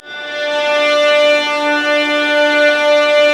Index of /90_sSampleCDs/Roland LCDP13 String Sections/STR_Violas FX/STR_Vas Sul Pont